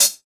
Closed Hats
HIHAT776.WAV